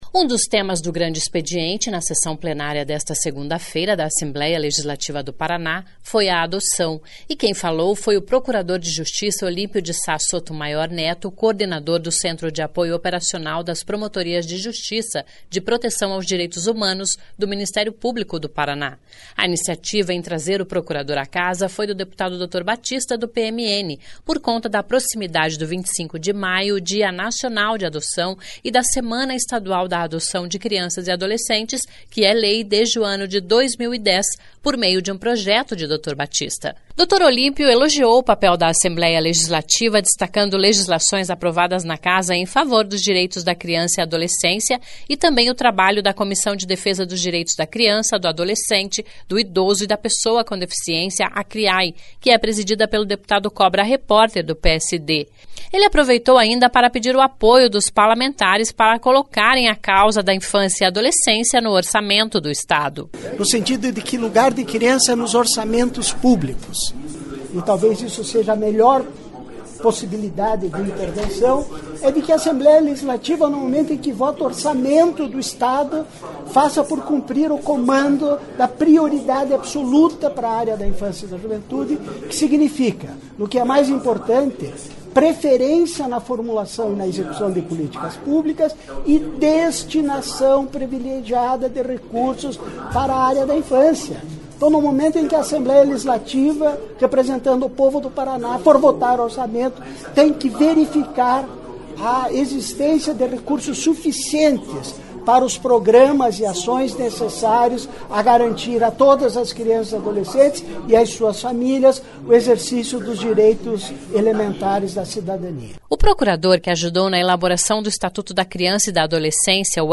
Um dos temas do Grande Expediente da sessão plenária desta segunda-feira (20) da Assembleia Legislativa do Paraná (Alep), foi a Adoção.
(sonora)